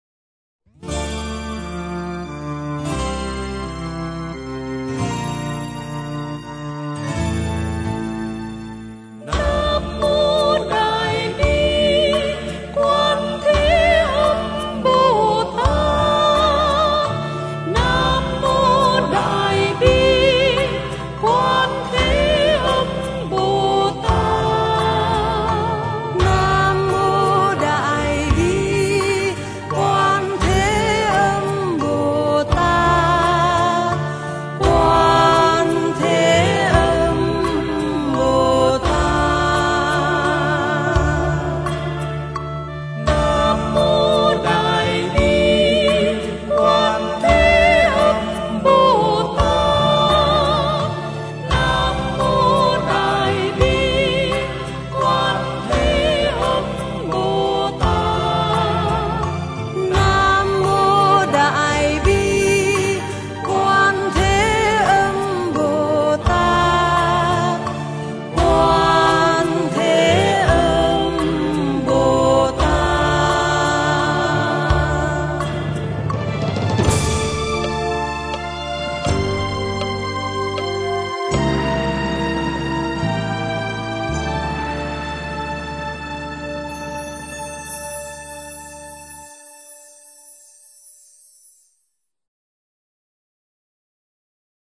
với các giọng ca